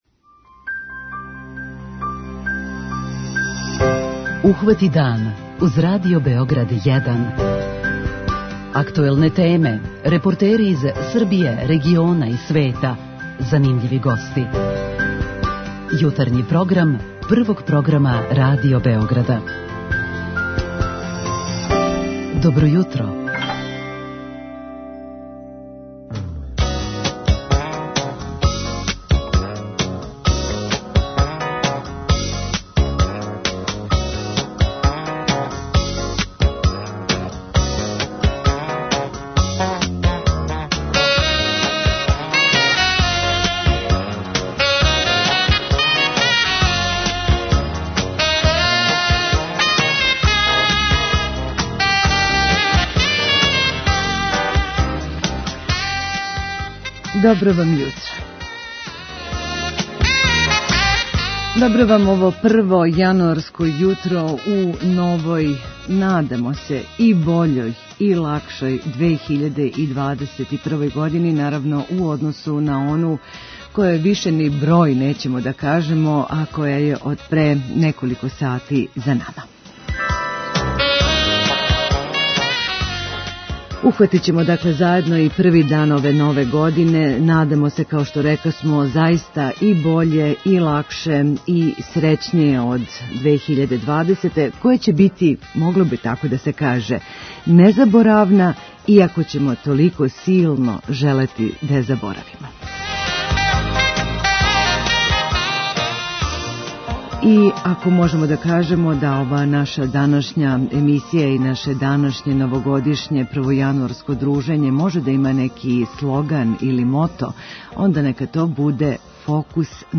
Њихово танано светло појачаћемо примећујући их и тако лакше поднети свакодневне дуге сенке проблема. преузми : 26.98 MB Ухвати дан Autor: Група аутора Јутарњи програм Радио Београда 1!